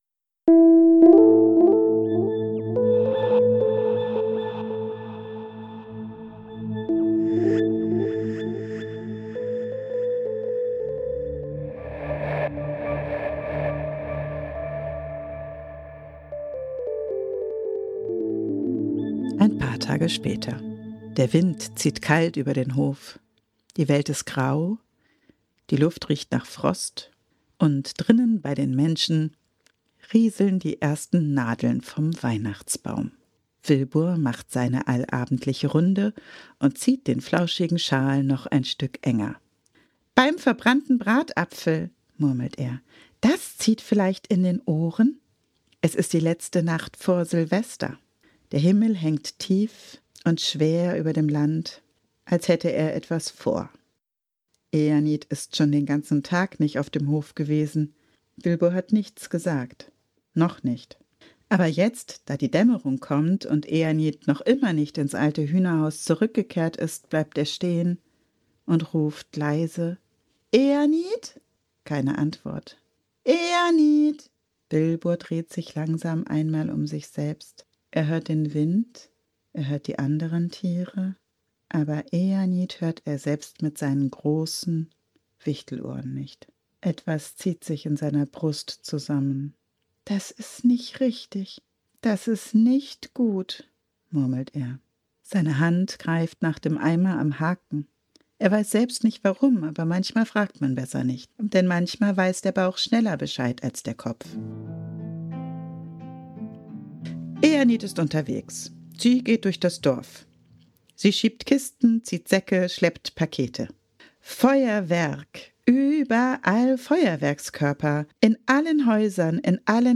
Ein moderner Märchen-Podcast für Kinder